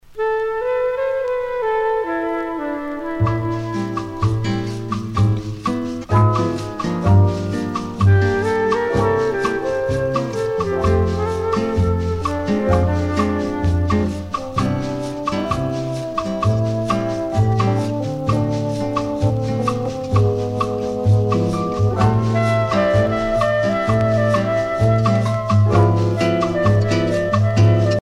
danse : bossa nova
Pièce musicale éditée